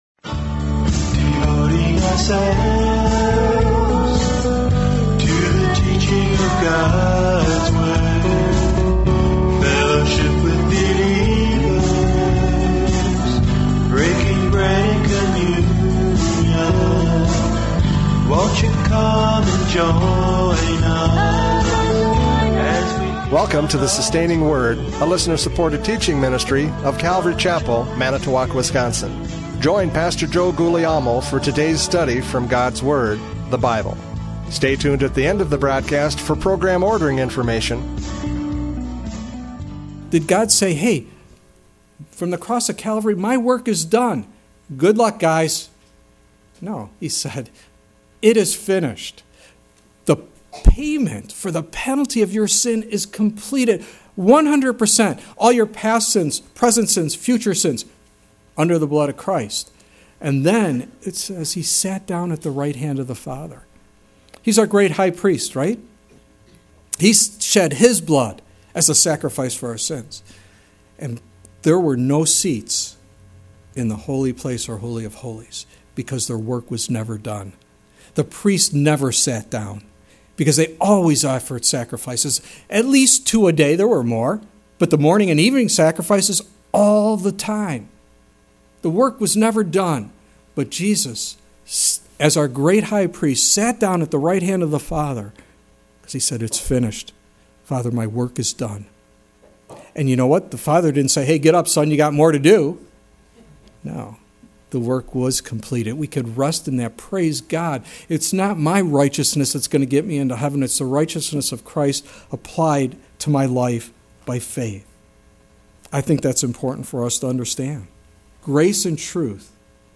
John 1:14-18 Service Type: Radio Programs « John 1:14-18 The Incarnation!